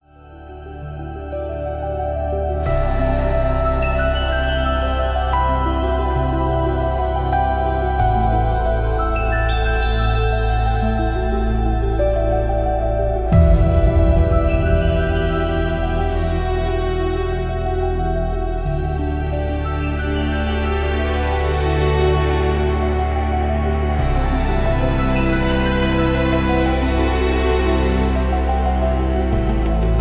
Background Music.